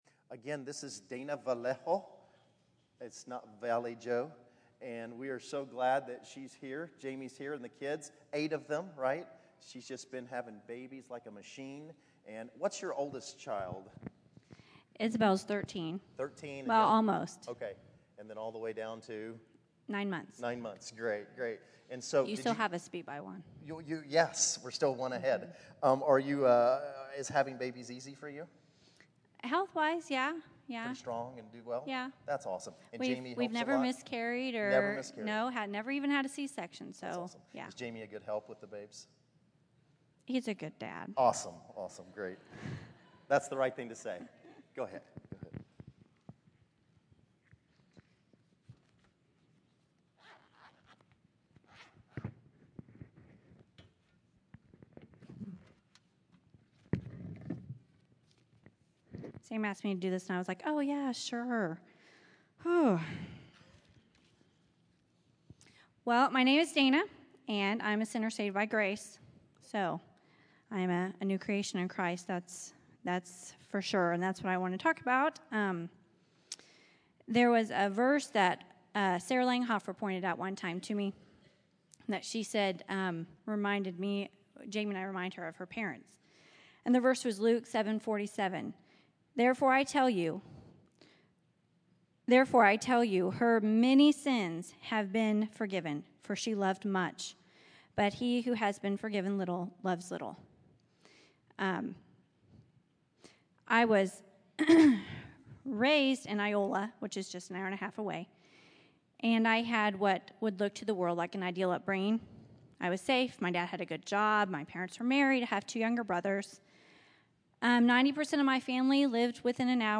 Testimonies